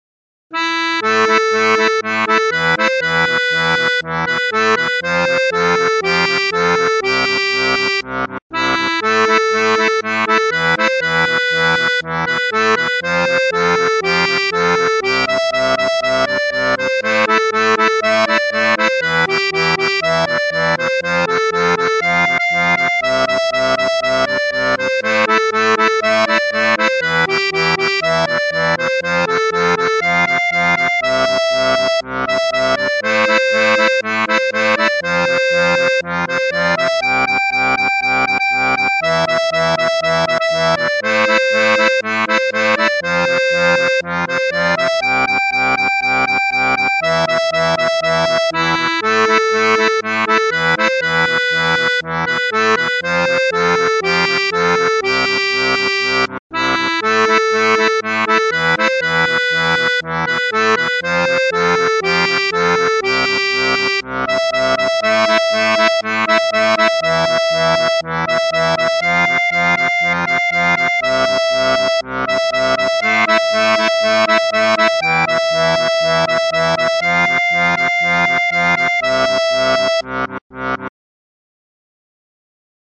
Pop-Rock